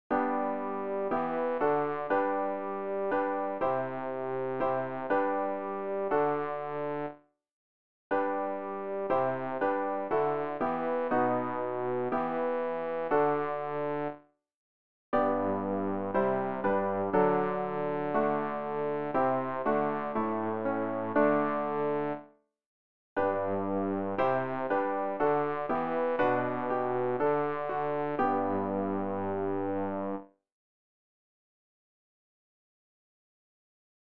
rg-010-wie-lange-willst-du-bass.mp3